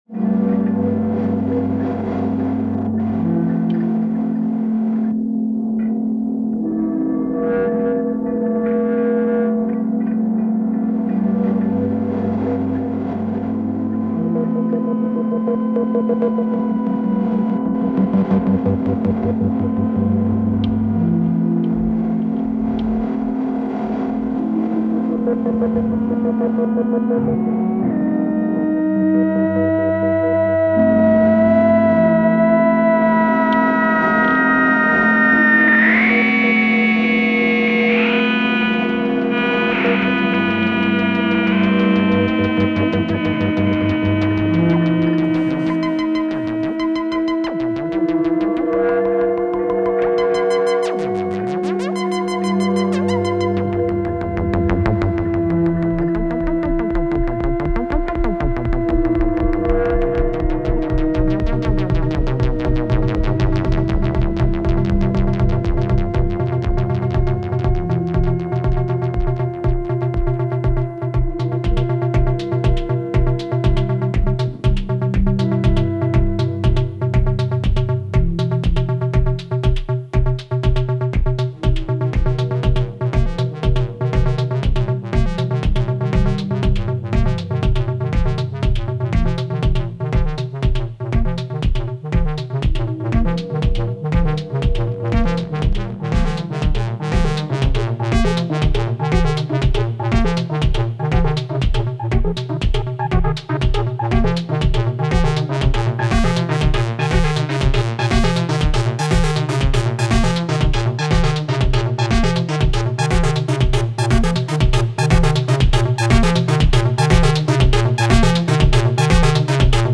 Modulare Synthesizer, Musik aus Strom
Sie surren, knarzen und fiepen: Modulare Synthesizer haben die Musiklandschaft nachhaltig verändert.
… und hier die Workshop Ergebnisse der einzelnen Tage: